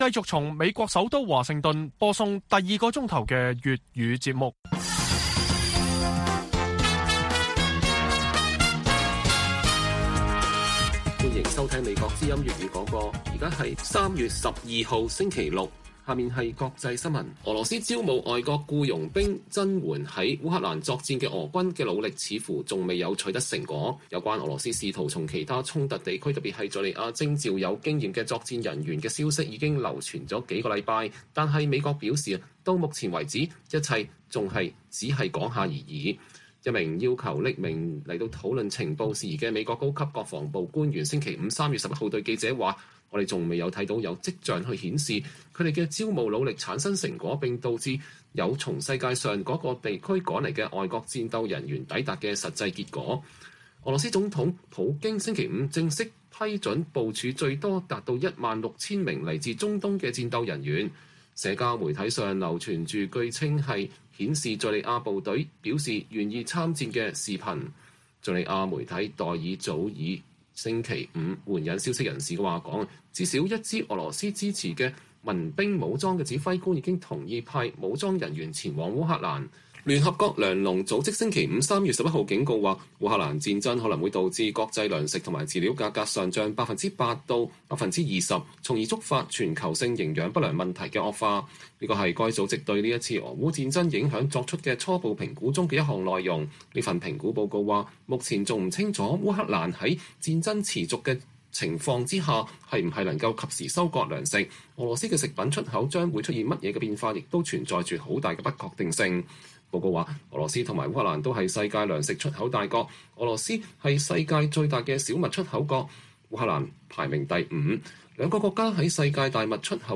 北京時間每晚10－11點 (1400-1500 UTC)粵語廣播節目。內容包括國際新聞、時事經緯和英語教學。